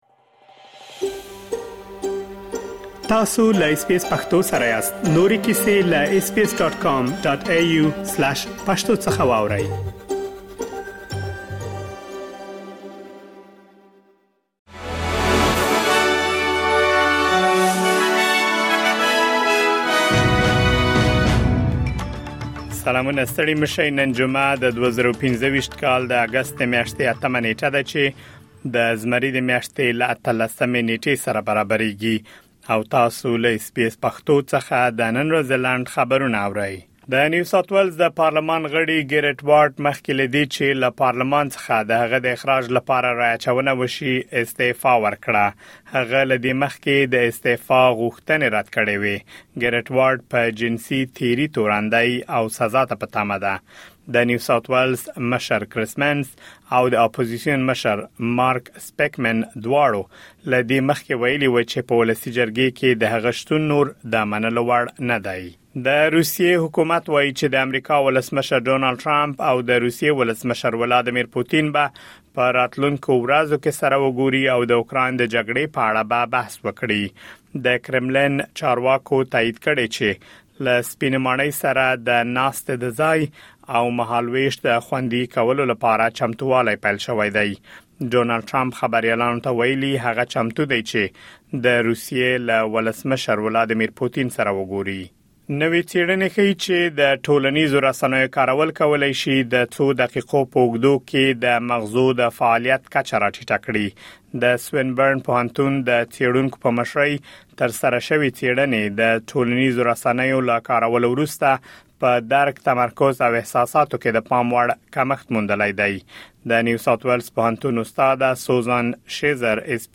د اس بي اس پښتو د نن ورځې لنډ خبرونه دلته واورئ.